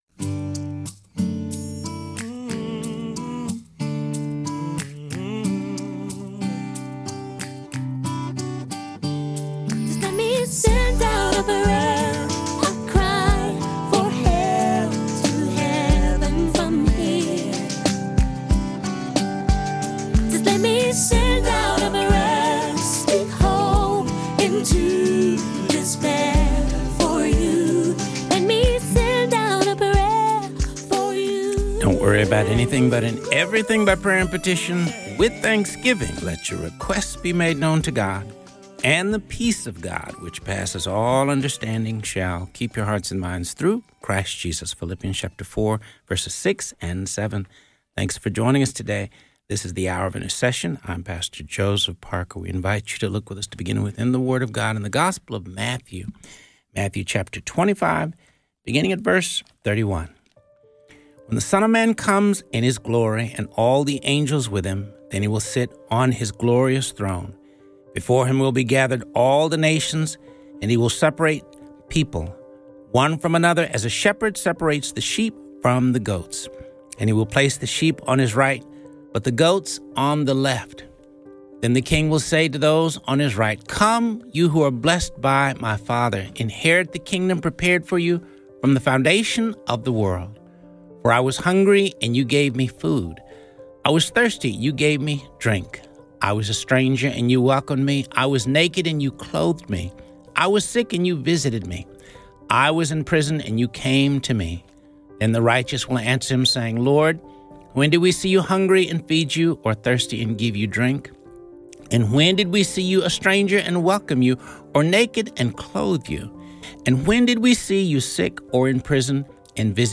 This show covers topics of prayer, intercession, the Word of God and features interviews with pastors and religious leaders.